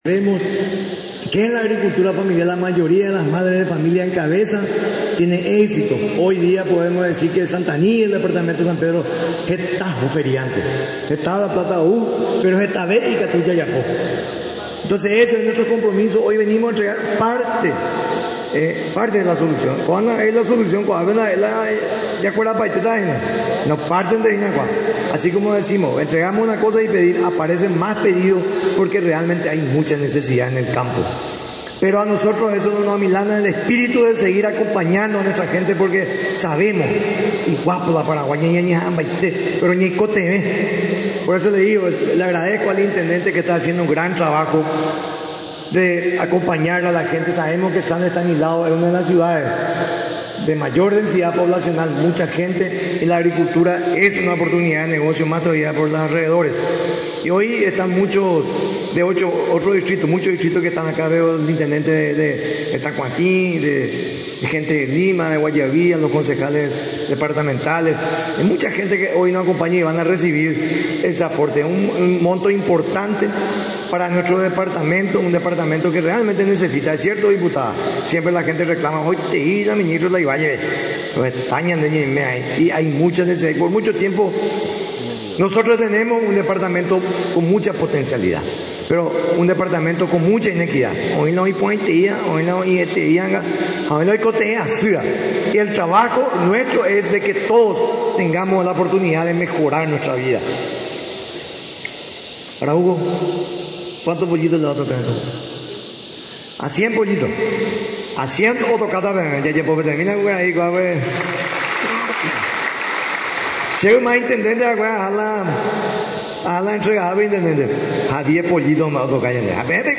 El ministro Dr. Carlos Giménez, realizó la entrega durante un acto en el distrito de San Estanislao.